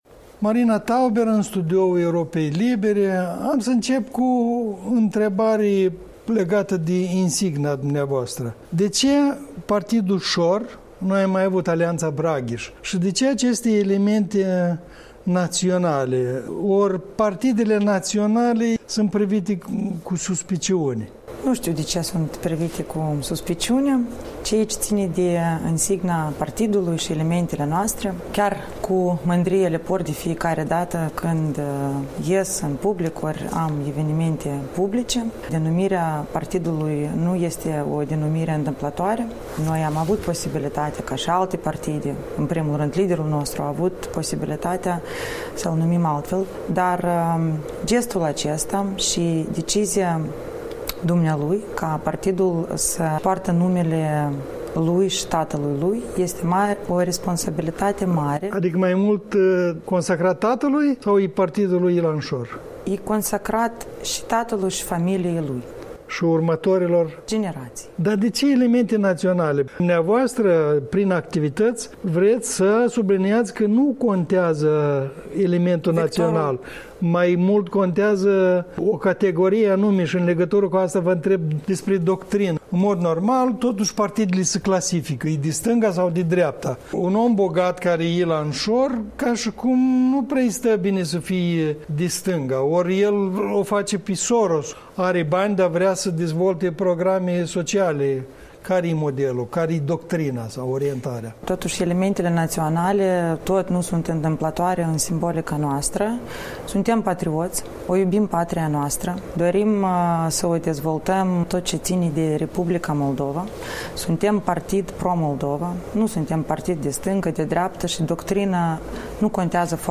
Interviu cu Marina Tauber, vicepreședinta PP„Șor”